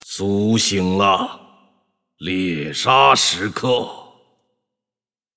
SFX王者荣耀后羿音效下载
SFX音效